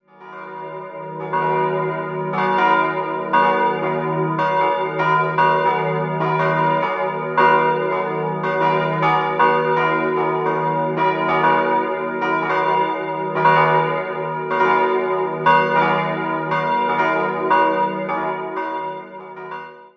Im Jahr 1898 schließlich erfolgte eine Erweiterung des Gotteshauses. 3-stimmiges E-Moll-Geläute: e'-g'-h' Die drei Gussstahlglocken wurden 1948 vom Bochumer Verein hergestellt.